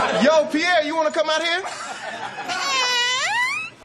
rb - carti vox.wav